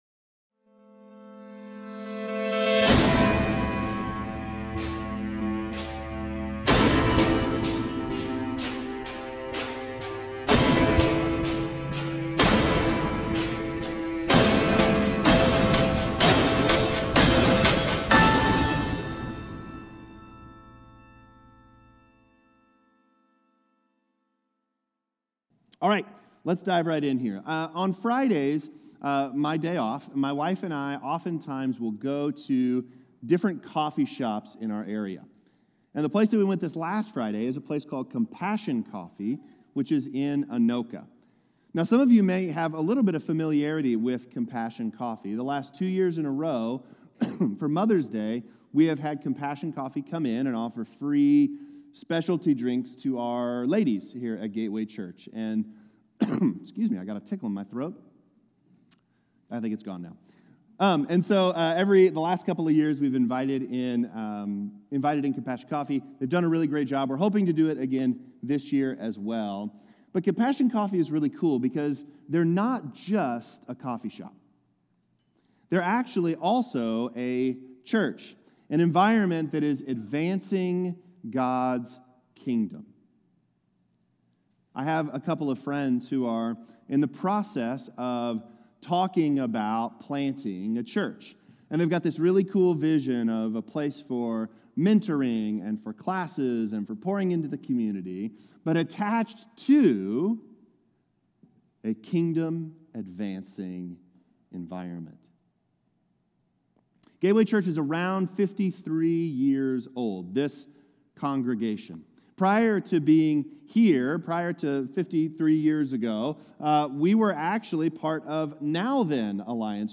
Jesus-is-Returning-Sermon-2.23.25.m4a